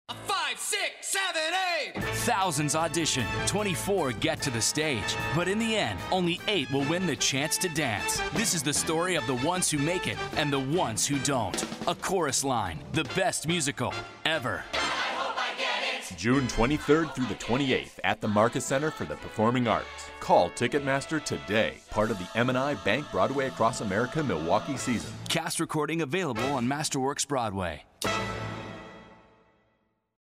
A Chorus Line Radio Commercial